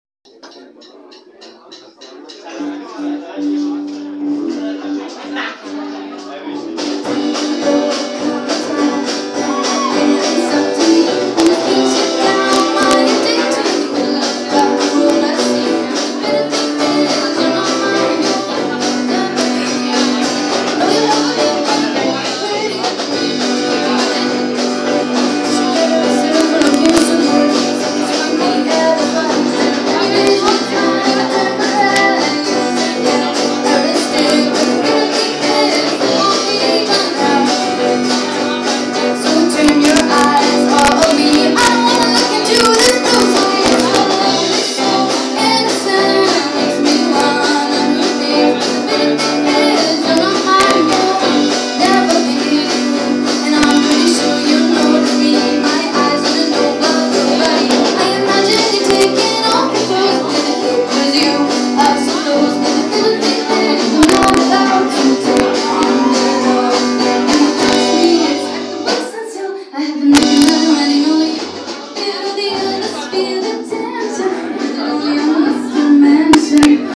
And last but not least kamen Maybe Yesterday mit ihrem Repertoire aus Folk,, Indiiepop, Country und Rock auf die Bühne. Leicht und gut gelaunt begeisterten auch sie das Publikum und alle sangen mit.
Hörprobe Band Maybe Yesterday vom Newcomer Konzert 26.9.24